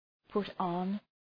put-on.mp3